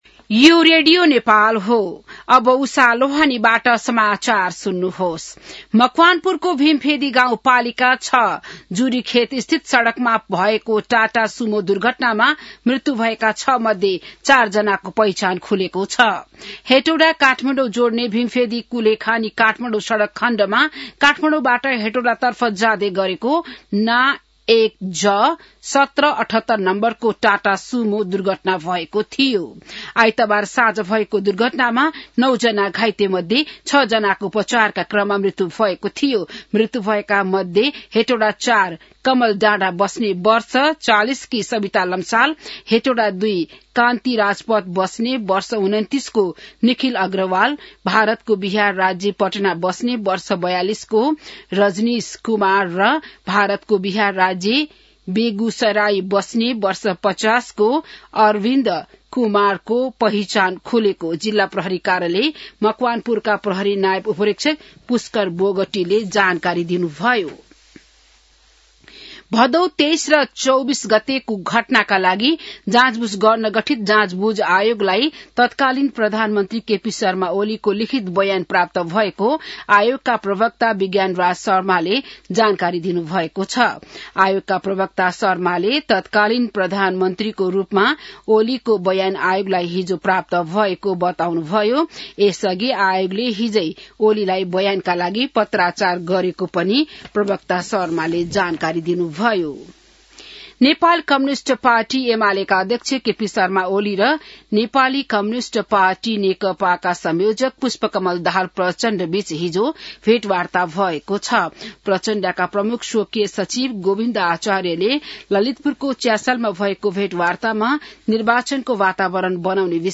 बिहान १० बजेको नेपाली समाचार : २१ पुष , २०८२